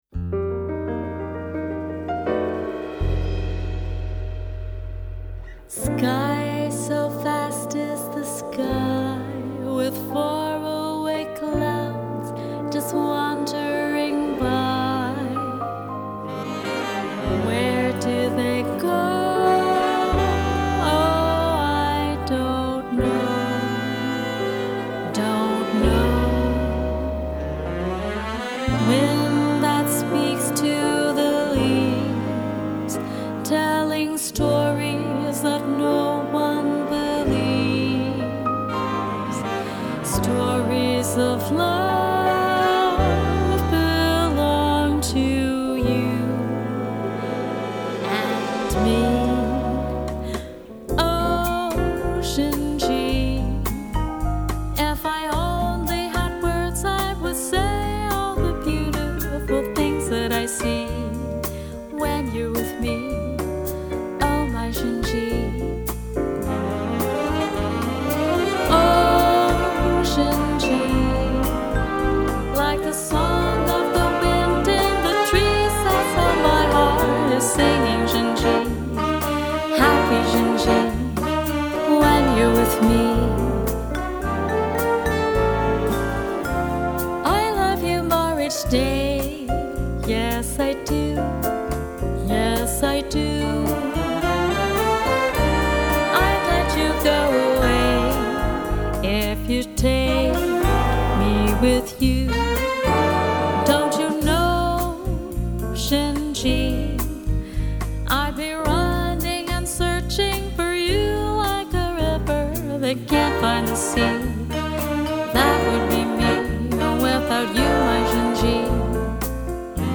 Voicing: Jazz Ensemble w/Vocal